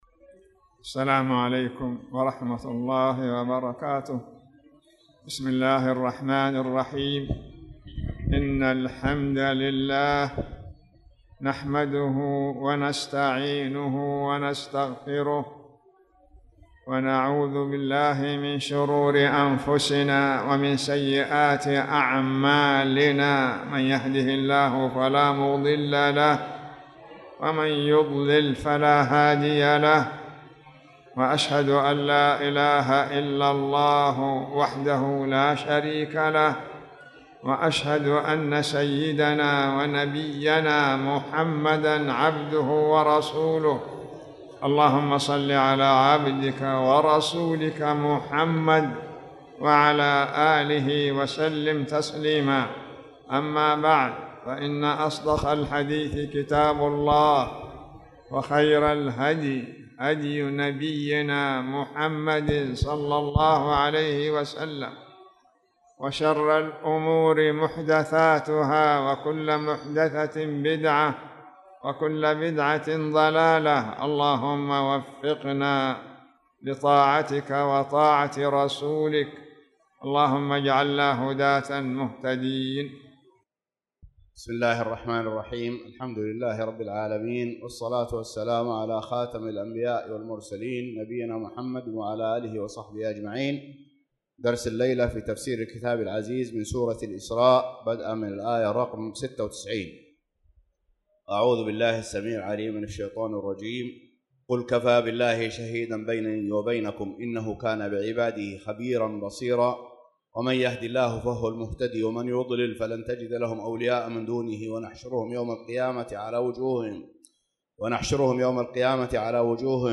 تاريخ النشر ٤ شعبان ١٤٣٧ هـ المكان: المسجد الحرام الشيخ